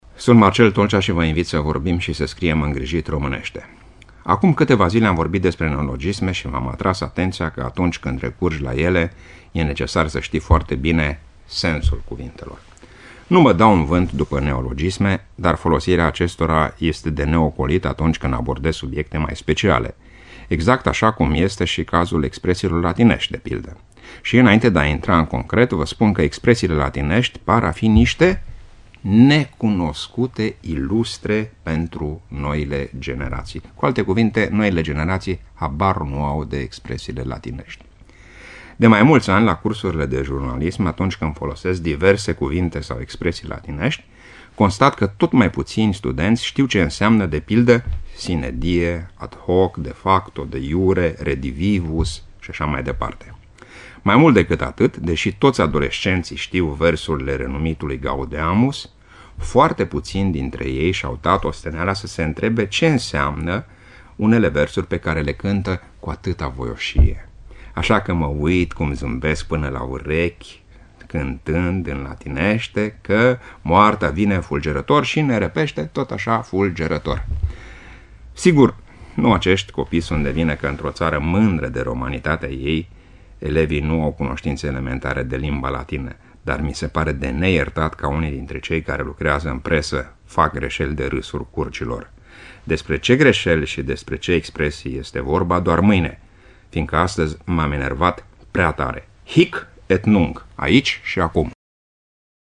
Rubricile sunt difuzate de luni până vineri inclusiv, în jurul orelor 7.40 şi 11.20 şi în reluare duminica dimineaţa, după ora 8.00.
(rubrică difuzată în 10 noiembrie 2015)